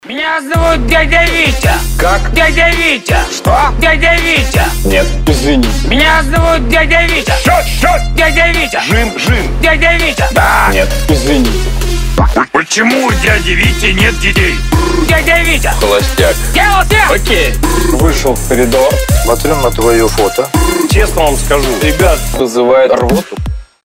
• Качество: 320, Stereo
веселые
Trap
смешные
riddim